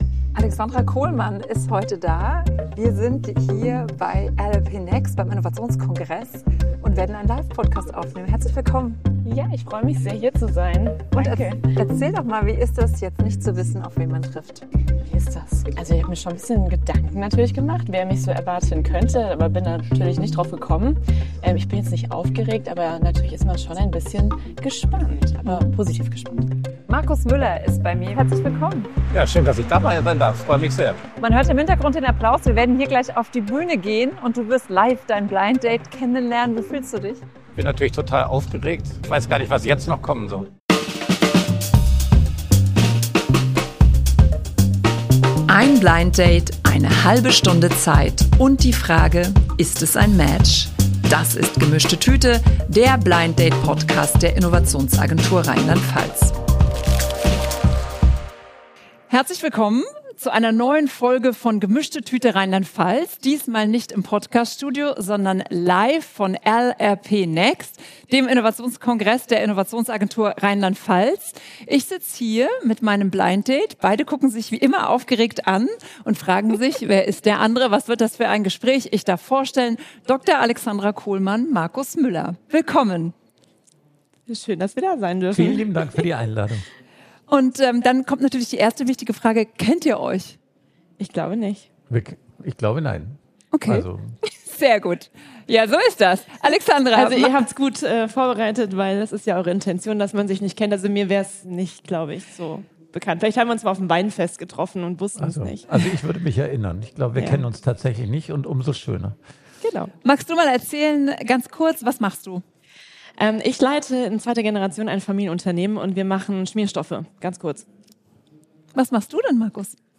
Im Podcast der Innovationsagentur RLP treffen sich zwei Persönlichkeiten, die sich bisher nicht kennen, und tauschen sich über ihre unterschiedlichen Perspektiven aus. Das Besondere: Sie bringen ein Geschenk mit, das etwas über sie erzählt.